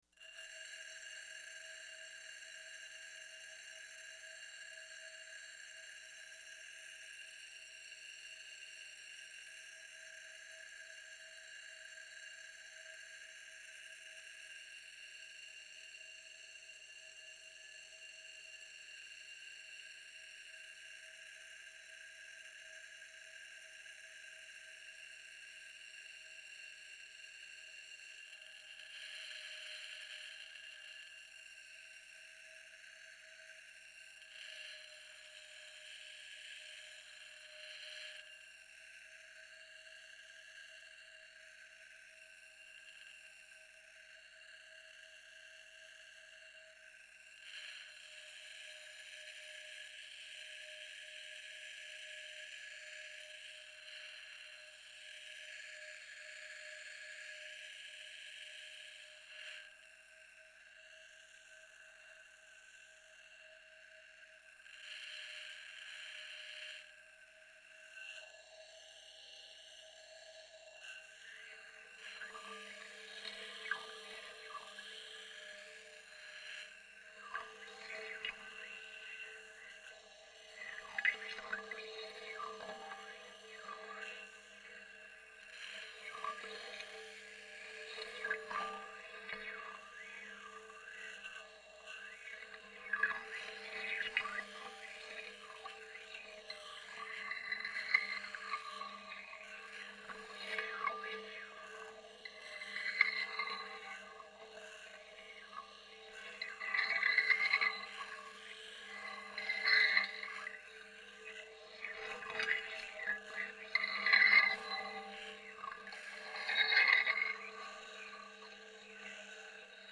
salt on glass, abalone and mussel shells